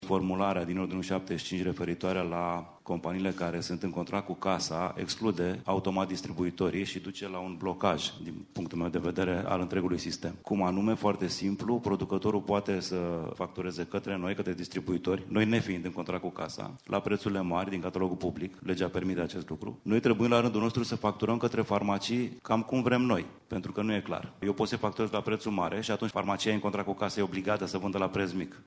Prin aplicarea acestui noi sistem de preţuri, piața farmaceutică s-ar putea bloca, susține unul dintre reprezentanții pieței farmaceutice